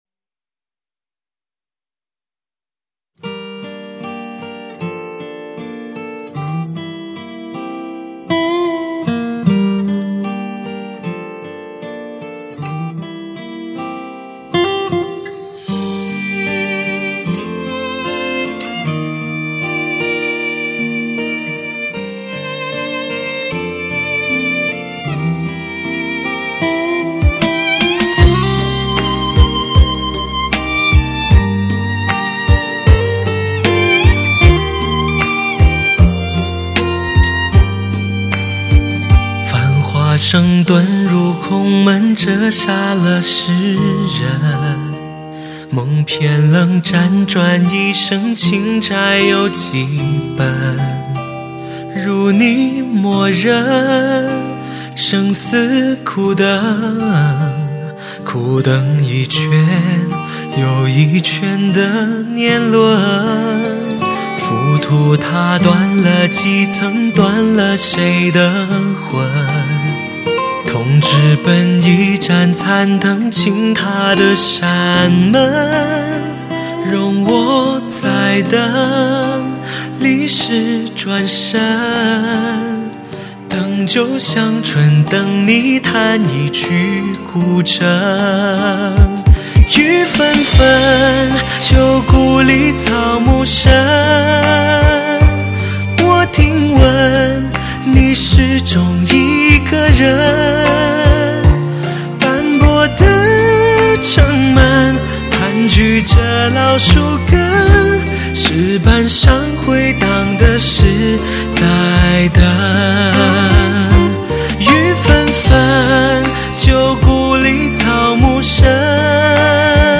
一辑音效极致惊艳的音乐发烧精品